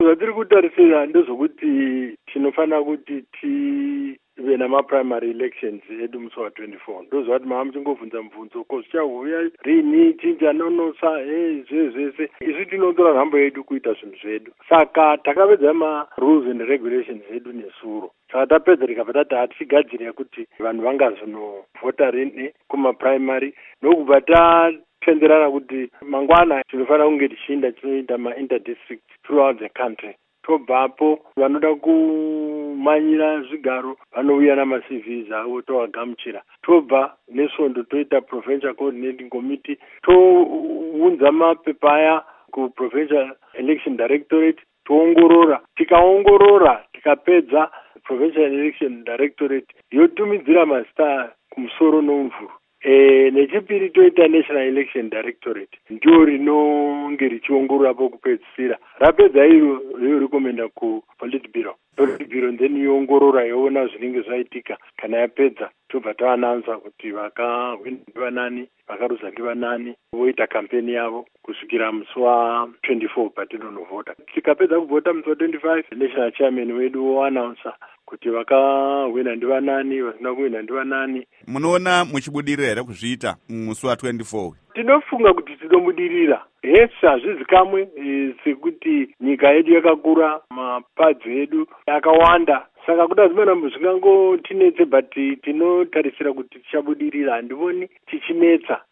Embed share Hurukuro naVaRugare Gumbo by VOA Embed share The code has been copied to your clipboard.